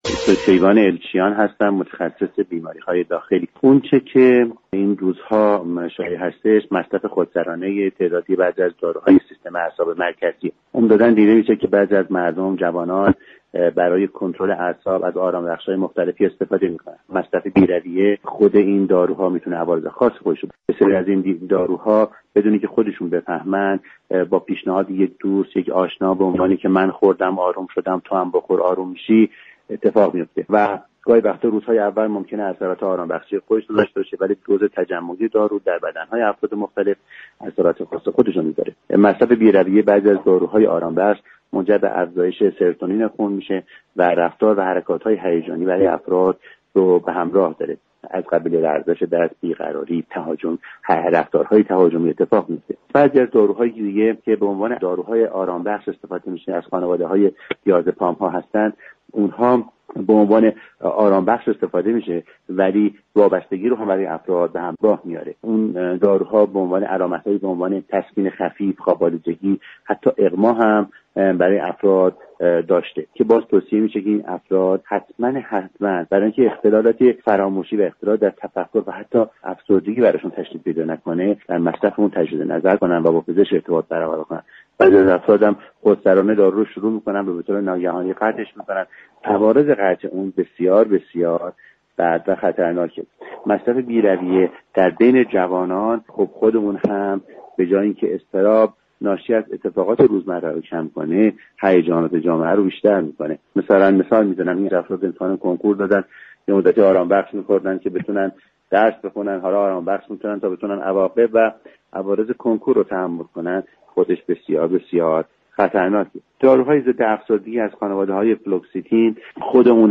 در گفت و گو با برنامه «رهاورد»
برنامه رهاورد شنبه تا چهارشنبه هر هفته ساعت 11:15 از رادیو ایران پخش می شود.